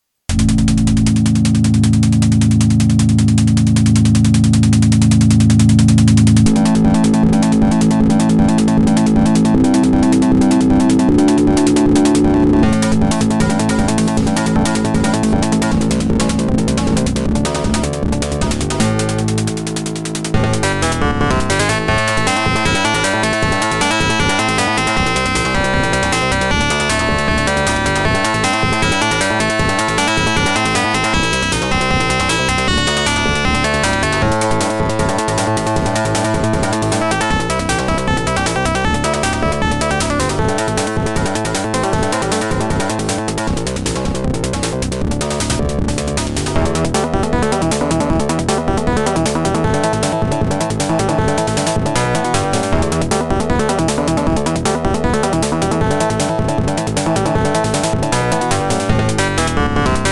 AD1815JS (TERRATEC Promedia Base-1)